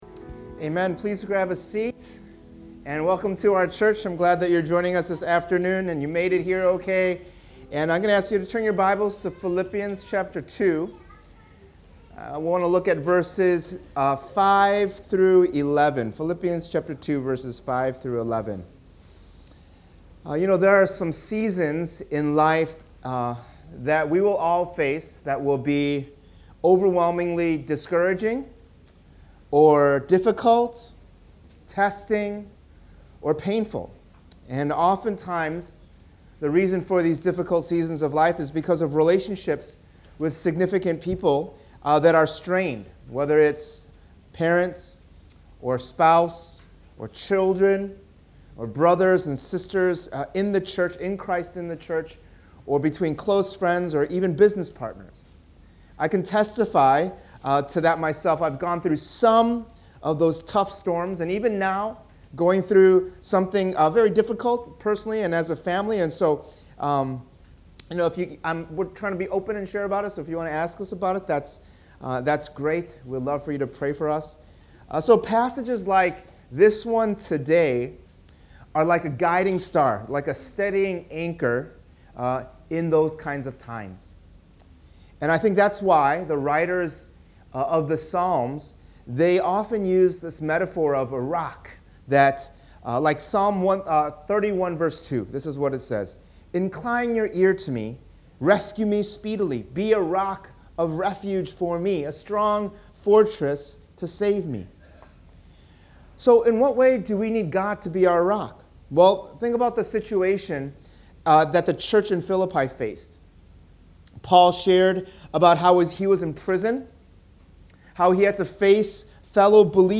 In this sermon series through Paul’s letter to the Philippians, we’ll learn together why, how, and when we can be joyful. Because of what Christ has done, we can rejoice always in him and in one another in the midst of any circumstance.